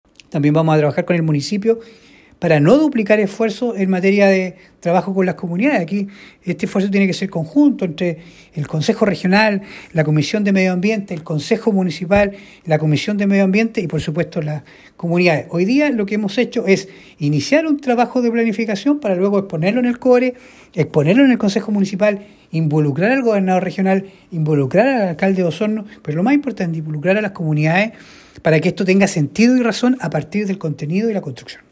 El Consejero Francisco Reyes indicó que para que el trabajo de esta mesa de descontaminación tenga sentido se deben involucrar todos los actores competentes como el municipio osornino, el Gobierno Regional y la comunidad en general.